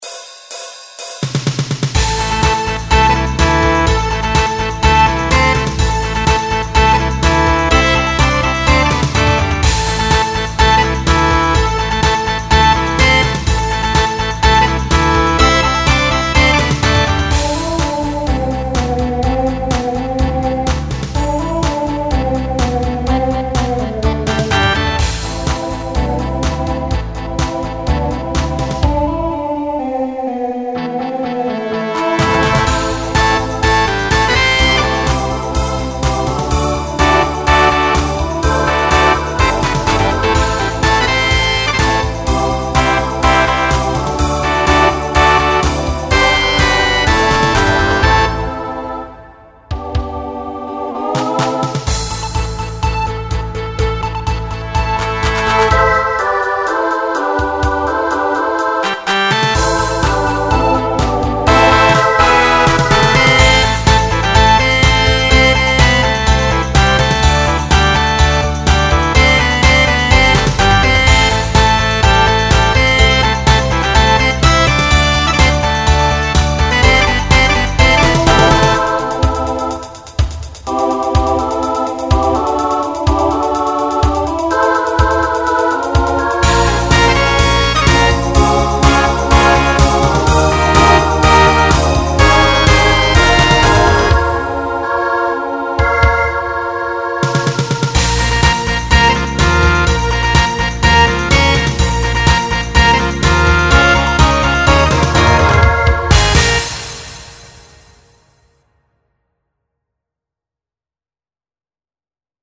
Genre Rock Instrumental combo
Transition Voicing SSA